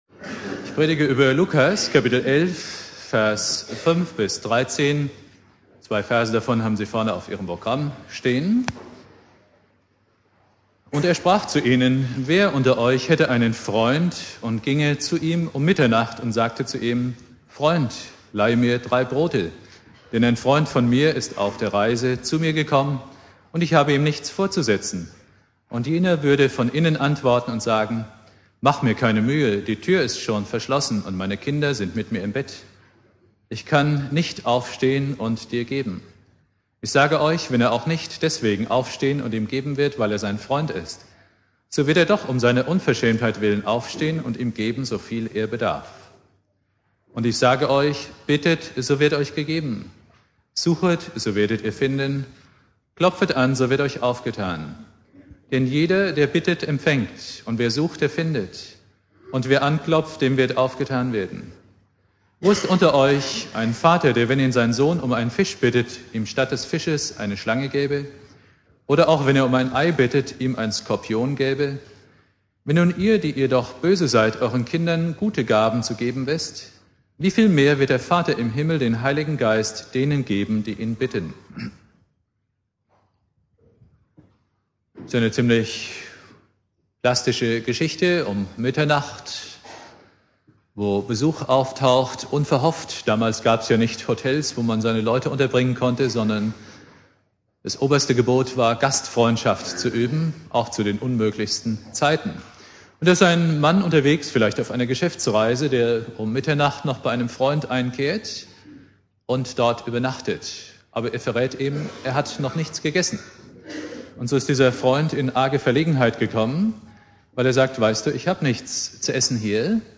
Predigt
Thema: "Vitamin B" (Konfirmation Obertshausen) Bibeltext: Lukas 11,5-13 Dauer